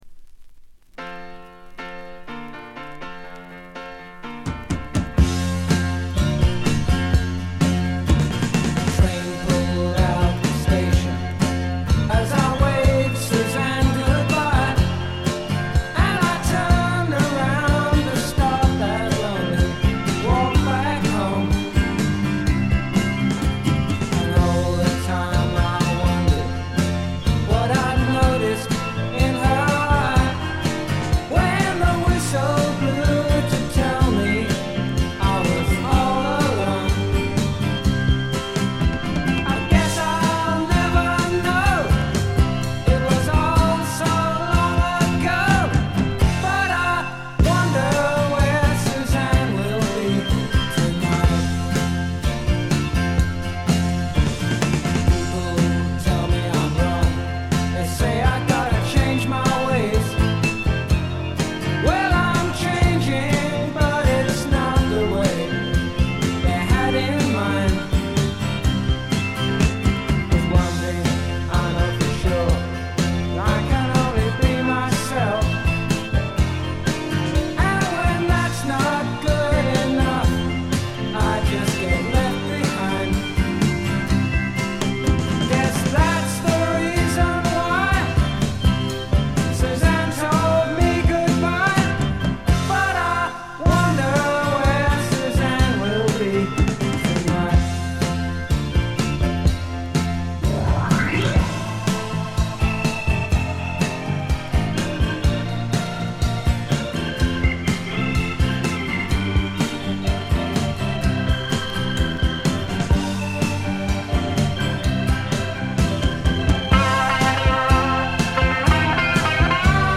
静音部で少軽いバックグラウンドノイズ程度。
試聴曲は現品からの取り込み音源です。